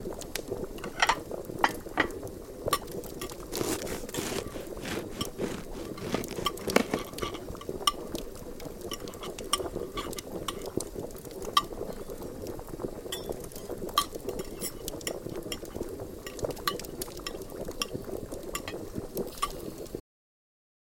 Assiettes-couverts-bouillon-cheminée-pain.mp3